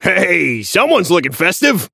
Shopkeeper voice line - He-ey, someone's lookin' festive!